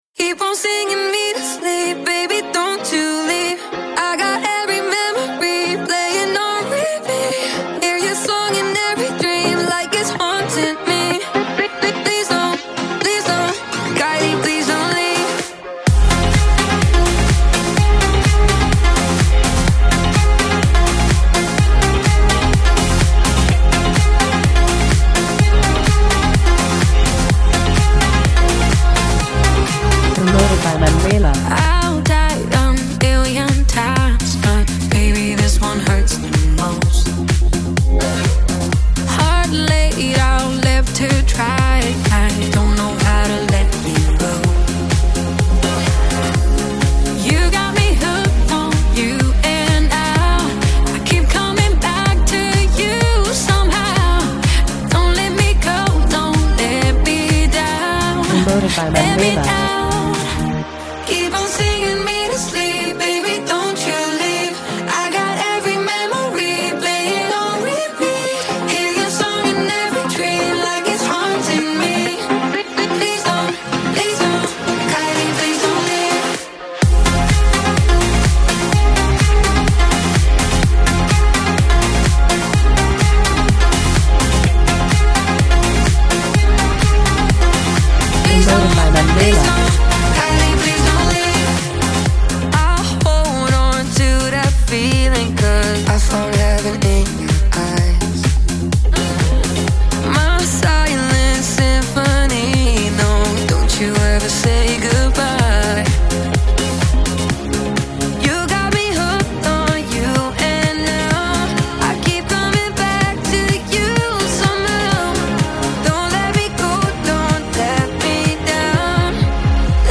mêlant nostalgie et son moderne
Ce remake dance allie émotion et énergie club